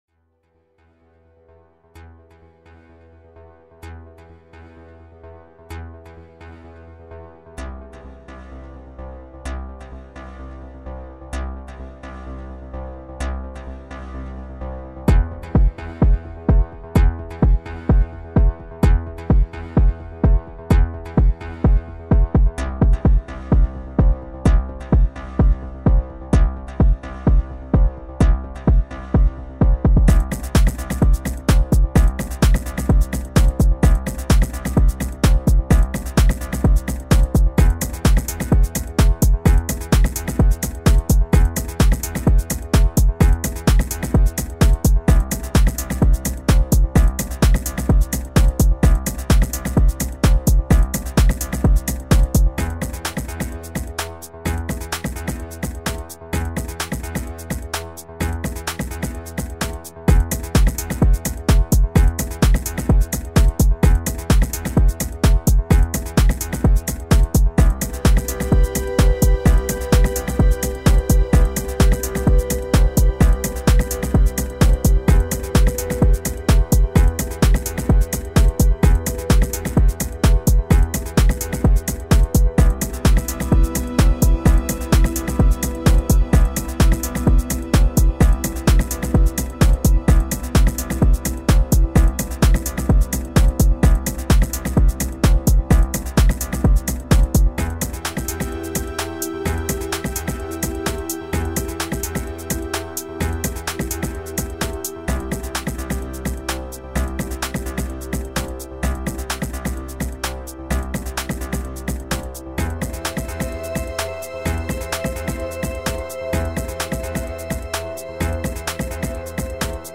a new WIP creation a nice piece of space music .
Ambient
retro 2022 ambient hypnotic love experimental electronic synthesizer hypnotize trance 90s